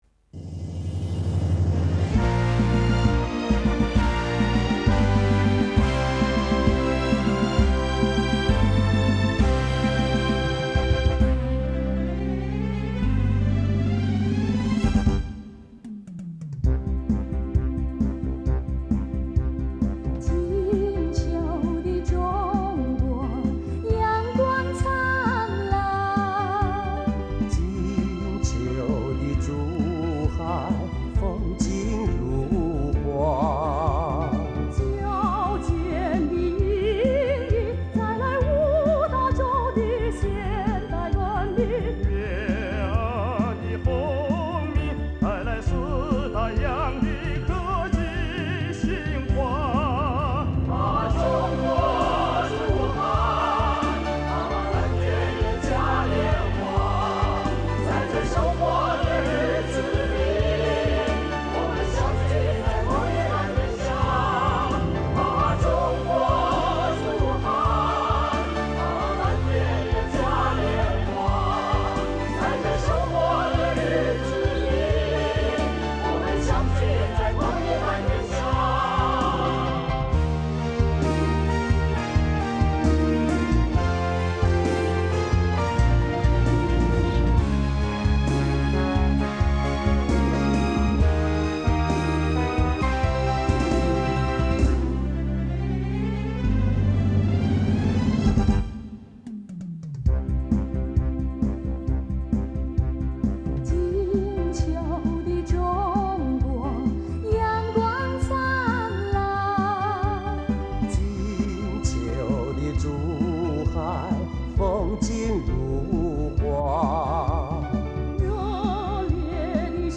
——首屆中國國際航空航天博覽會主題歌